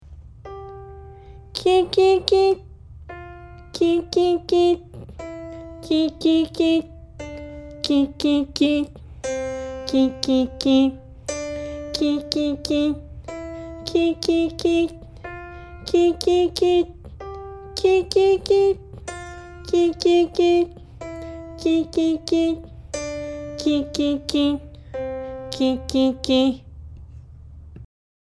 地声後ろ上「キ」.mp3